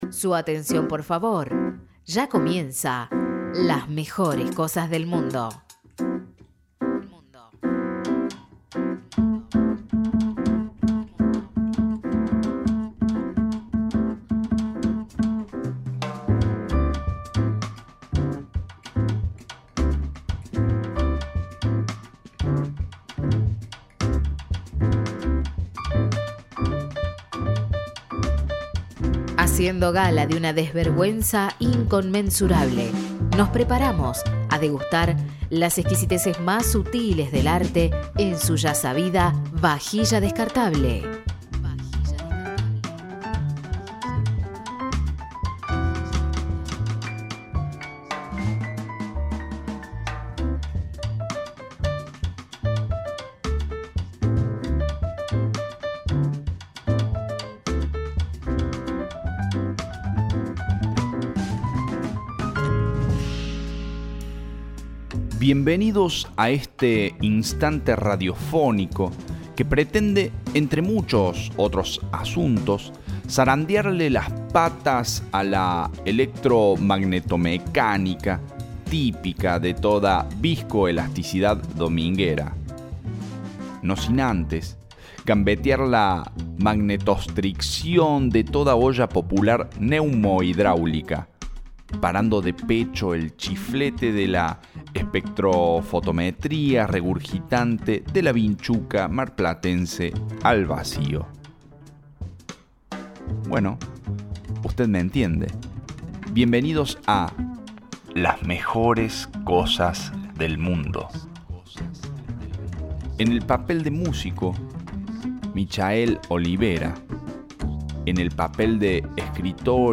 En este capítulo leemos un hermoso texto de Claudio Eliano (s. III)